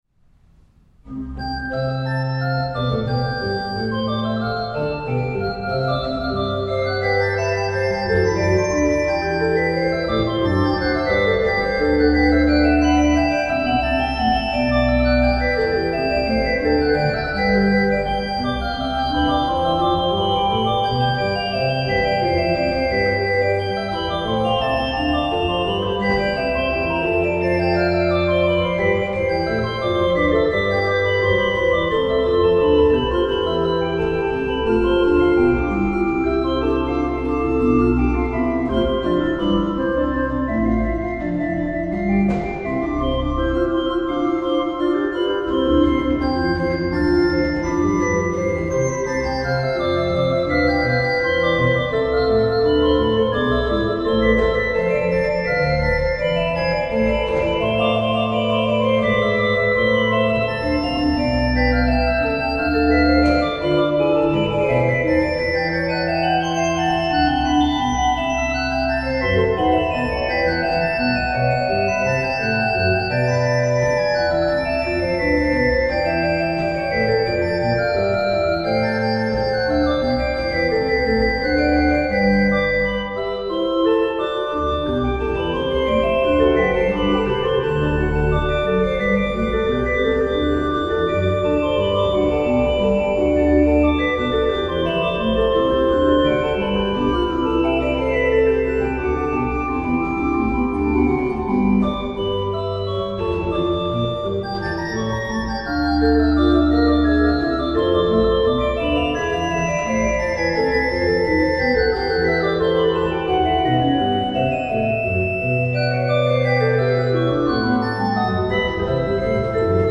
Auch heute hören wir Orgelmusik
in St. Josef eingespielt wurde.
Johann Sebastian Bach – Triosonate in C BWV 529 – Allegro | Orgel